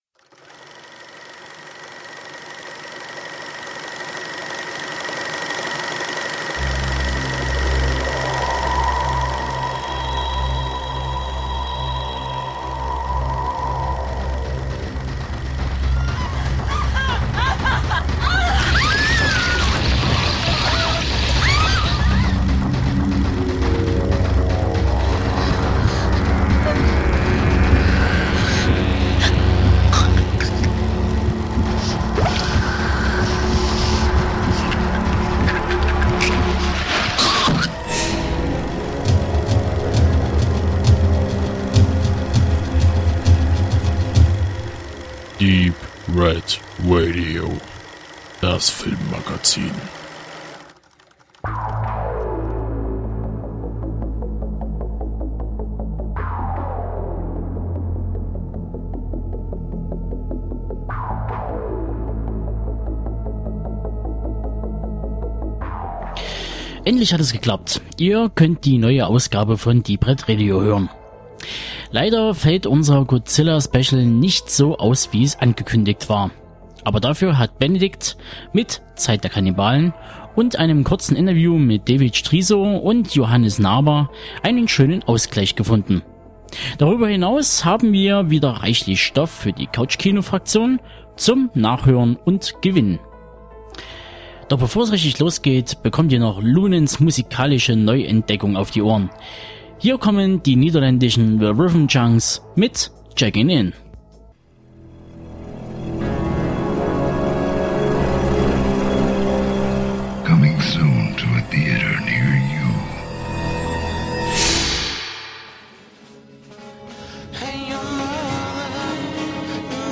Beschreibung vor 11 Jahren Seit dem 15.05.2014 stapft Godzilla wieder durch die Kinos und kurze Zeit später macht das wohl beliebteste Monster der Filmgeschichte auch einen Stippvisite bei Deep Red Radio. Von einem Betriebsausflug ins Kino heil zurückgekehrt, berichten wir Euch in einer offenen Runde von den Eindrücken, die wir mitgenommen haben.